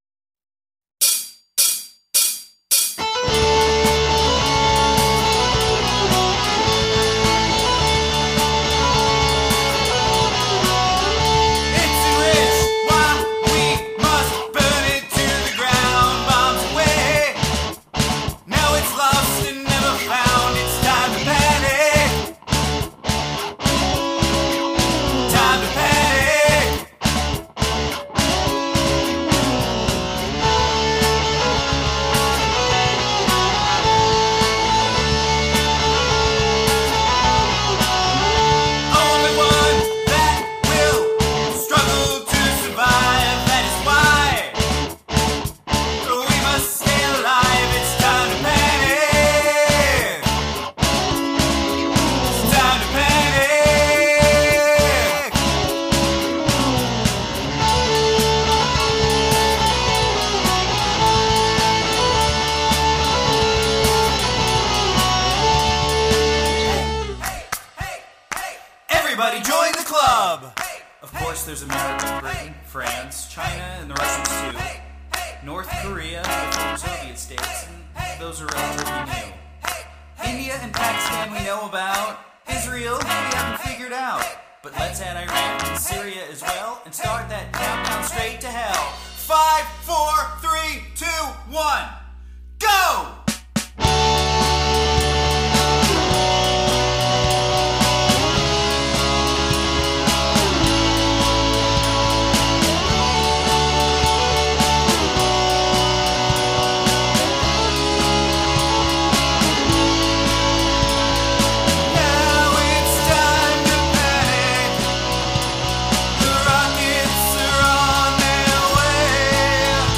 Song must include audible counting